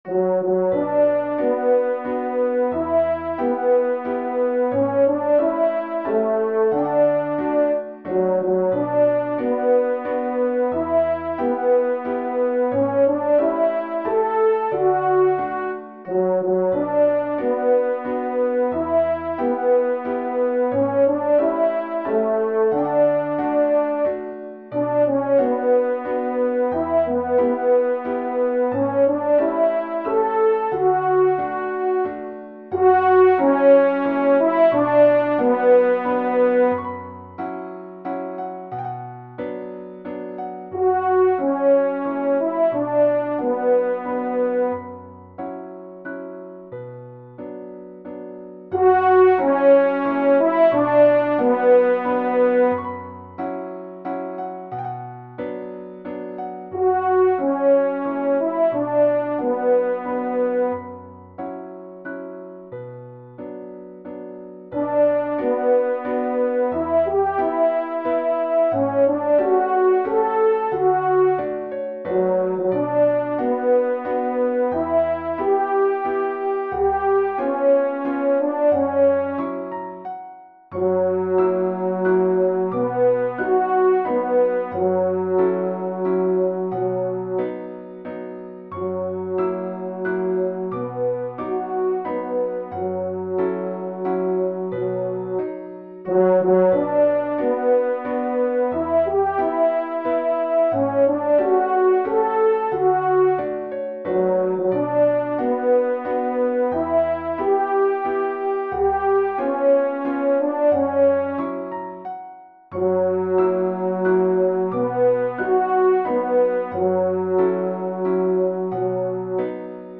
TROMPE en Exergue